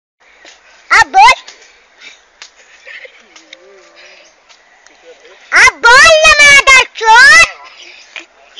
A direct and challenging Hindi phrase, 'Ab Bol' translates to 'Speak now' or 'Say something now.'